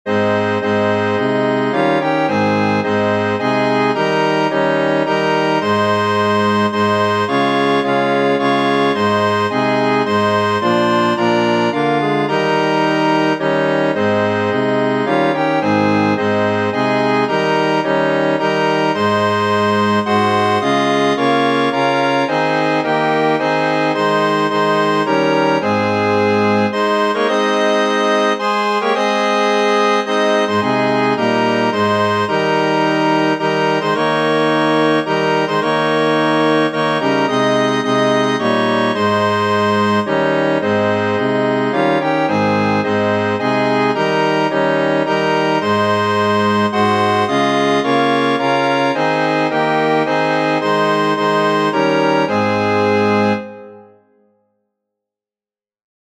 Órgano